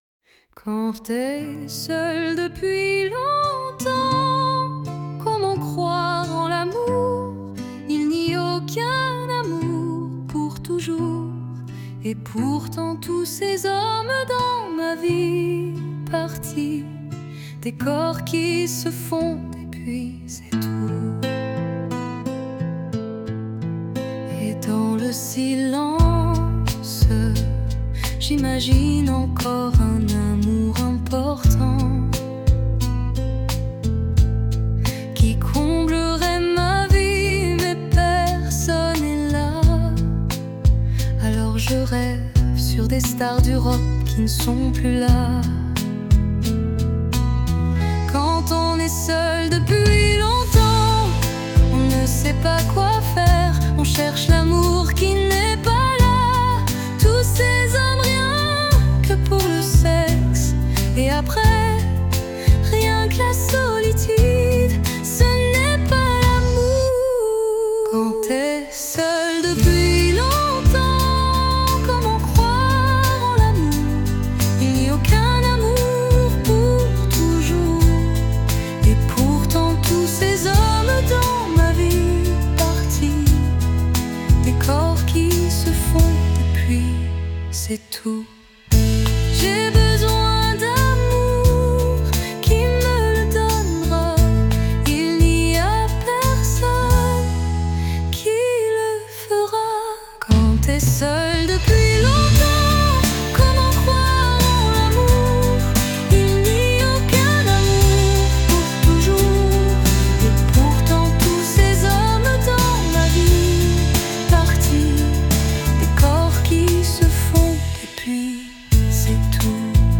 Sinon, j'ai encore fait une chanson en IA